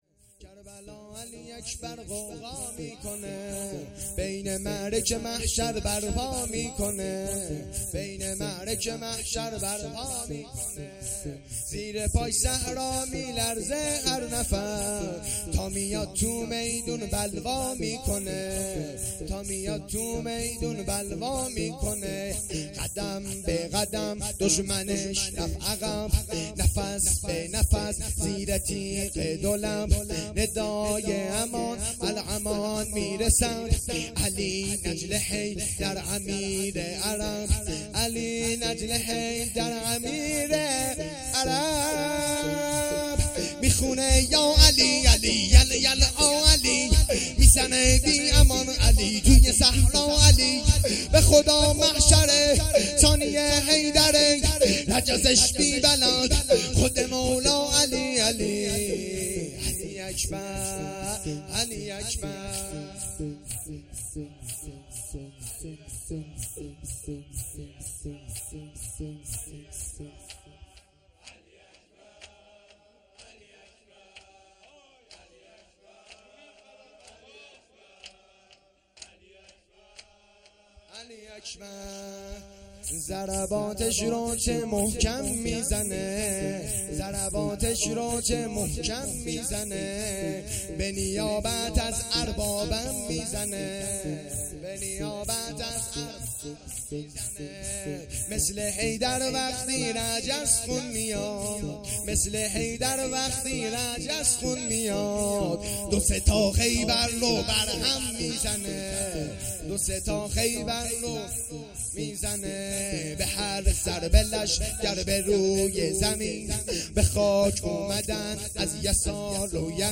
شور
محرم الحرام ۱۴۴۳